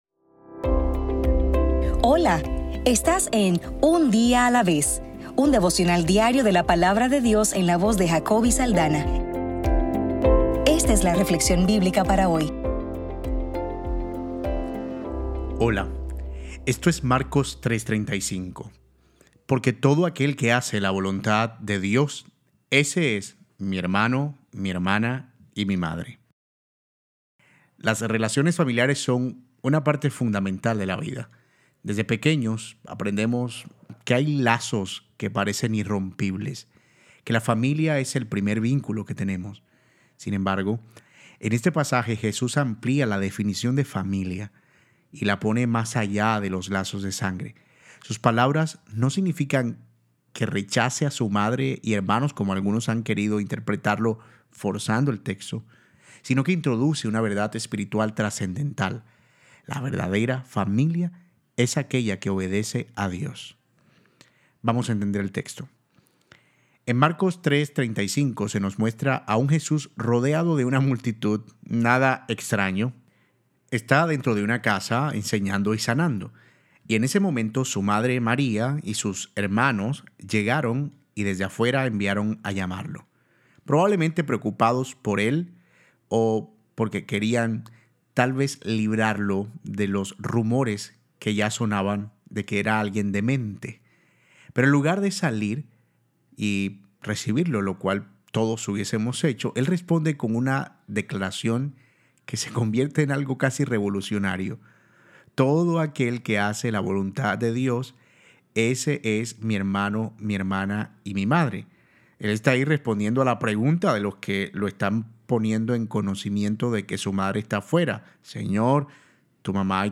Devocional para el 31 de enero